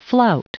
Prononciation du mot flout en anglais (fichier audio)
Prononciation du mot : flout